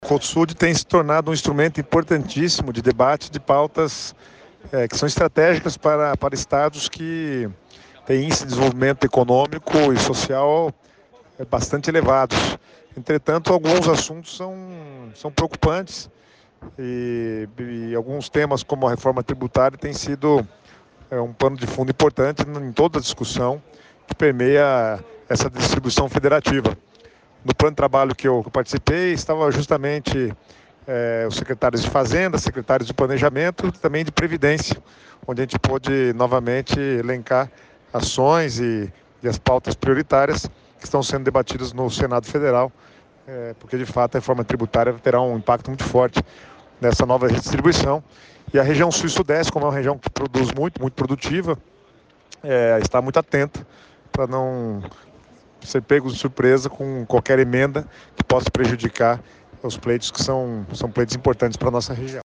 Sonora do secretário Estadual do Planejamento, Guto Silva, sobre os Grupos de Trabalhos da reunião do Cosud em São Paulo | Governo do Estado do Paraná